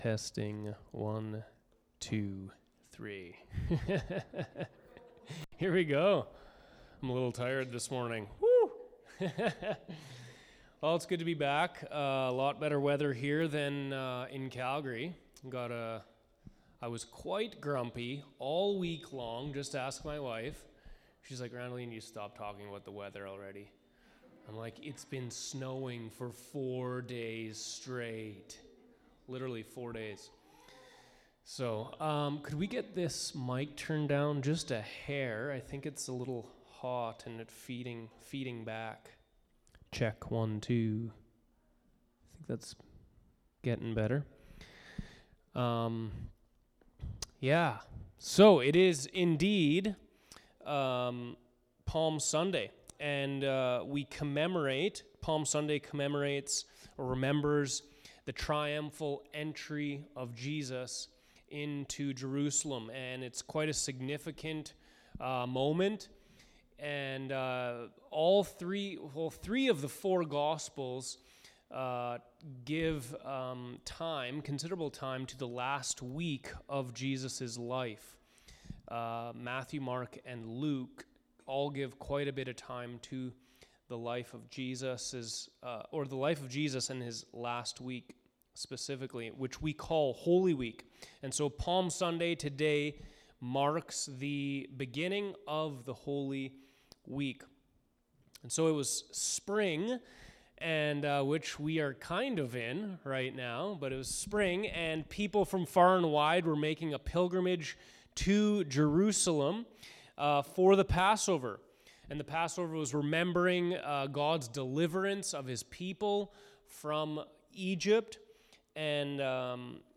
Sermons | Provost Community Church